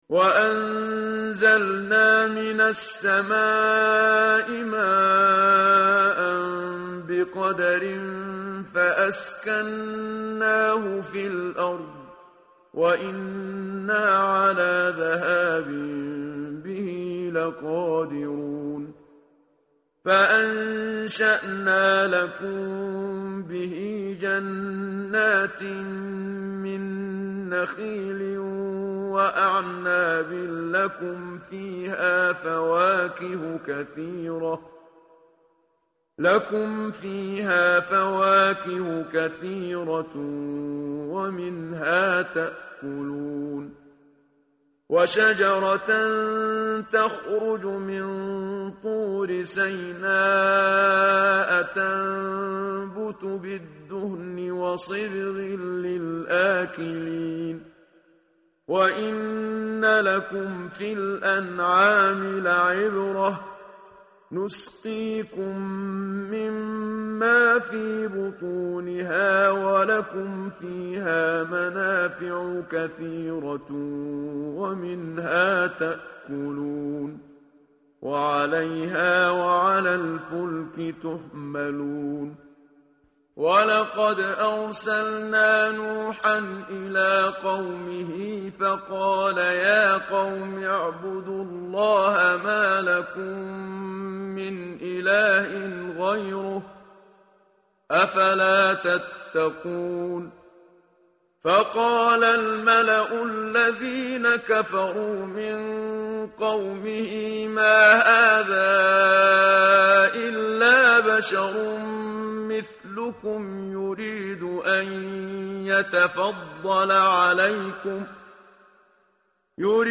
tartil_menshavi_page_343.mp3